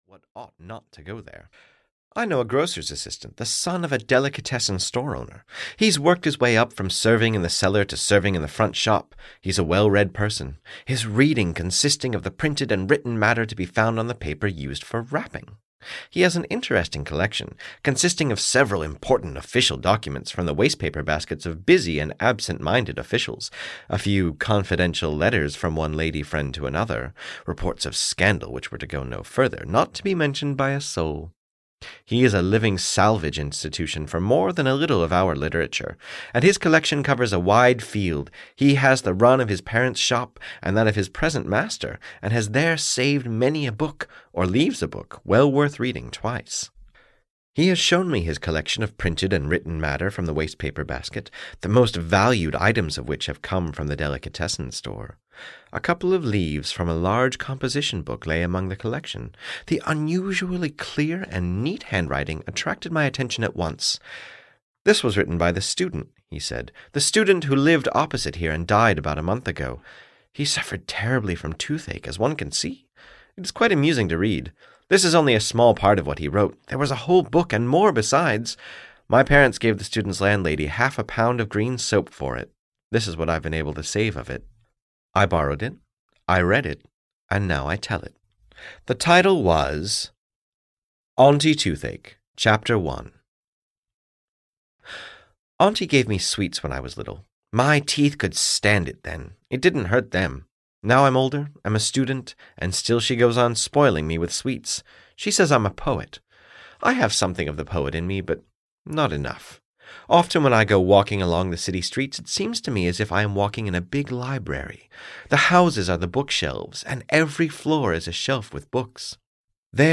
Aunty Toothache (EN) audiokniha
Ukázka z knihy